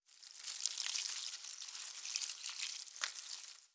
SFX_WateringPlants_01_Reverb.wav